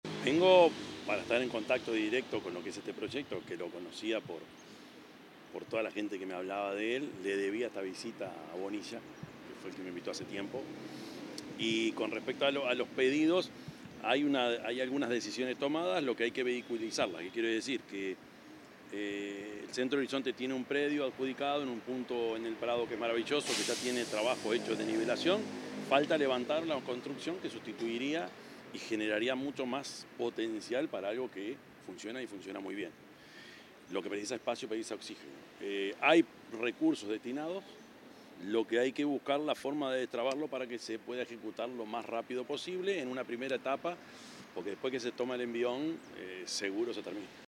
Declaraciones del presidente Yamandú Orsi
Tras la conmemoración, el mandatario dialogó con los medios informativos.